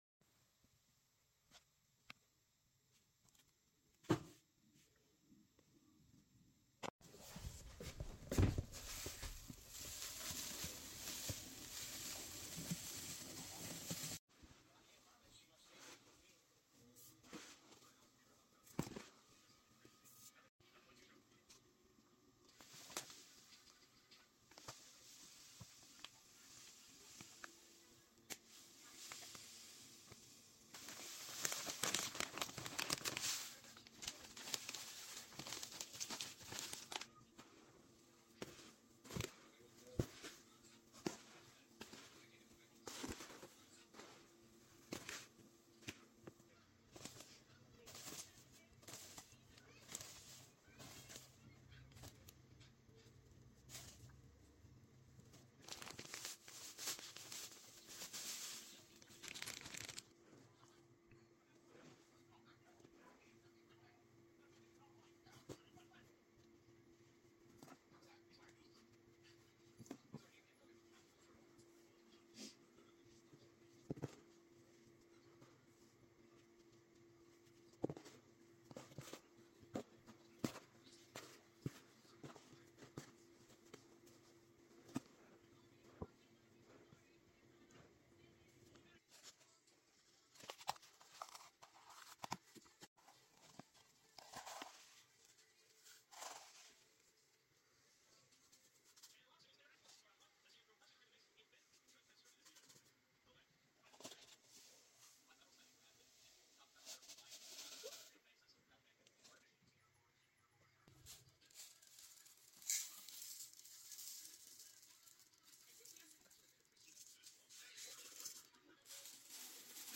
Making Some Of My Laundry Sound Effects Free Download